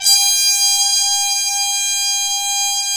Index of /90_sSampleCDs/Roland LCDP06 Brass Sections/BRS_Harmon Sect/BRS_Harmon Tps S